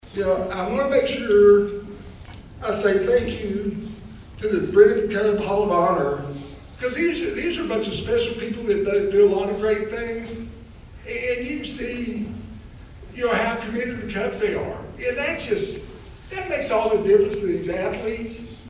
The Brenham Cub Football Hall of Honor welcomed four new members into their ranks before a packed house at the Blinn College Student Center this (Friday) afternoon.